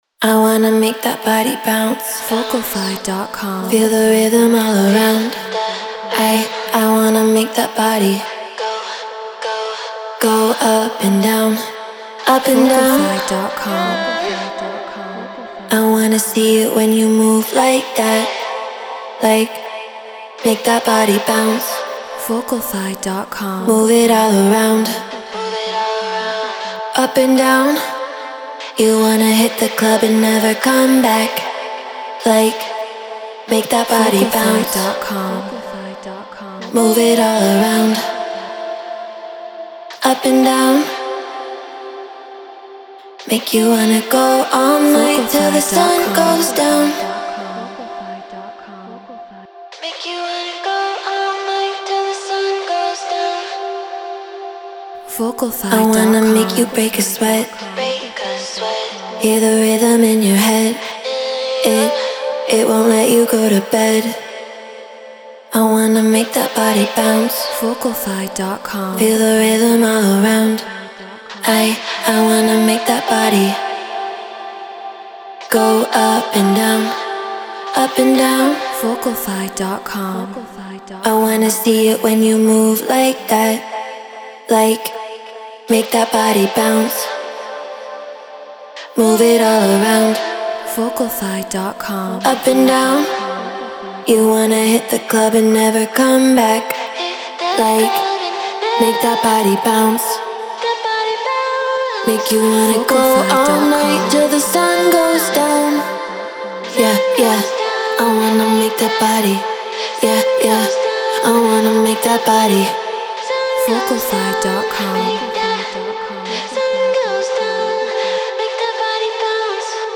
Hard Dance 147 BPM Dmin
Shure SM7B Scarlett 2i2 4th Gen Ableton Live Treated Room